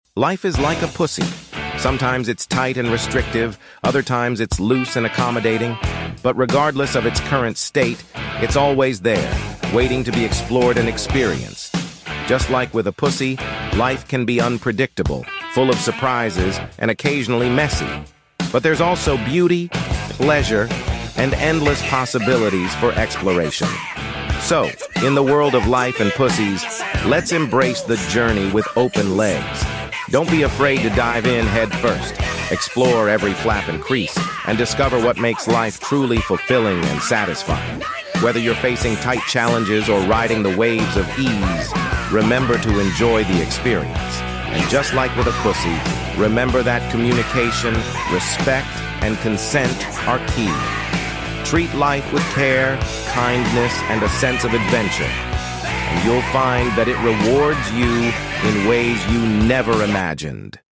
FWD 1500hp LADA sound effects free download